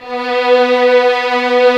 Index of /90_sSampleCDs/Roland LCDP13 String Sections/STR_Violins III/STR_Vls6 mf%f M